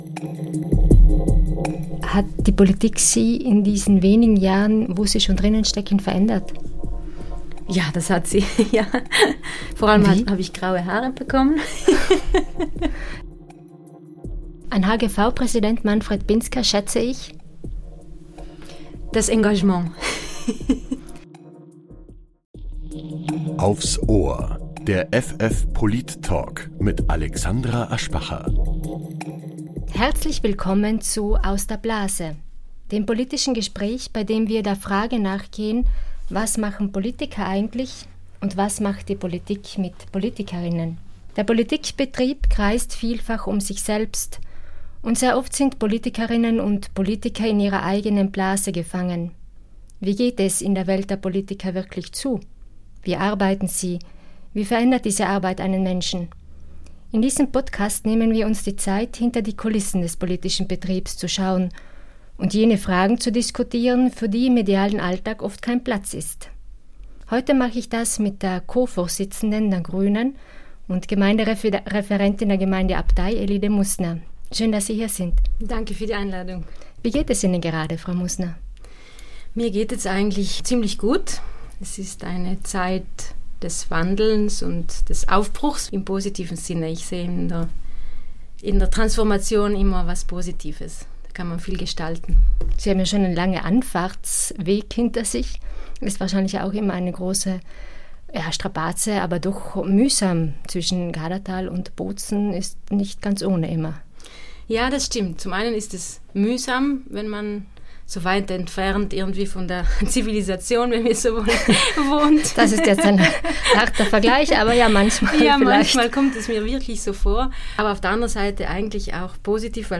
Der Polit-Talk